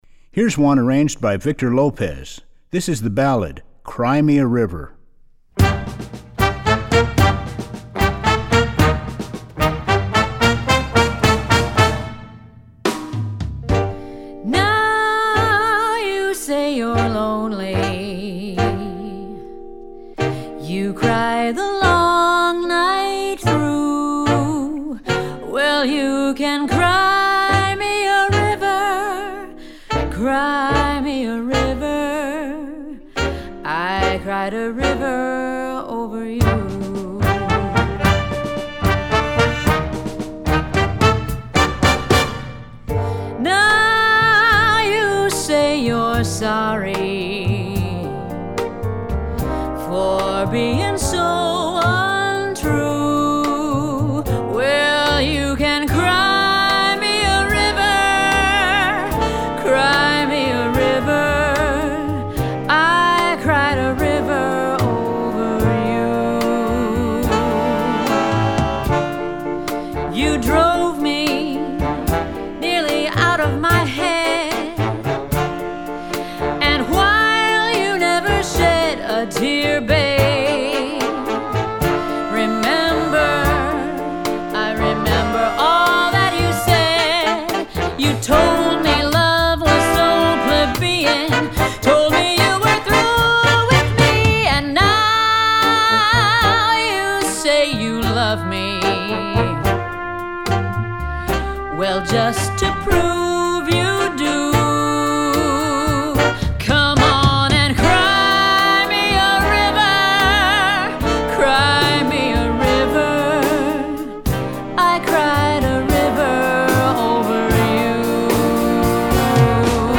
Voicing: Big Band with Vocal